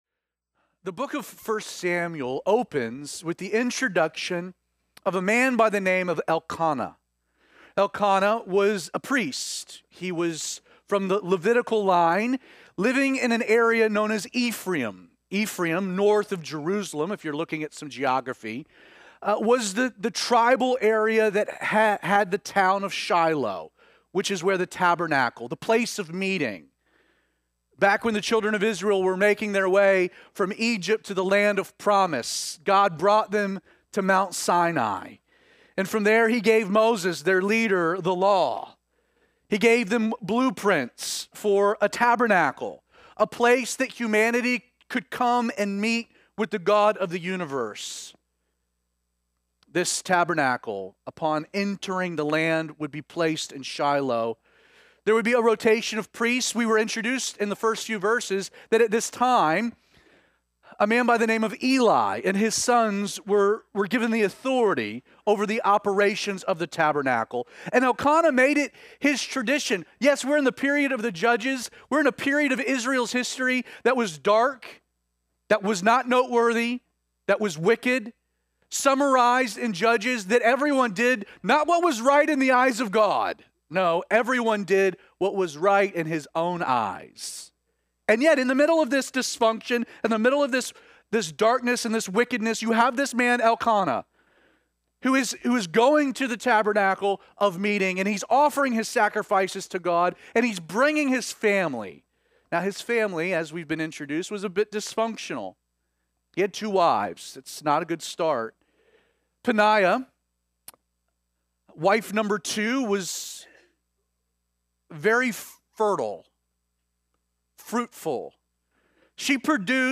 These message are recorded live at Calvary316 on Sunday mornings and posted later that afternoon.